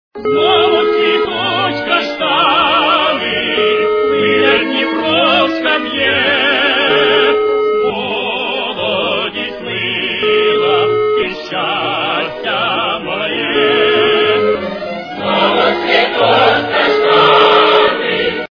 народные
качество понижено и присутствуют гудки.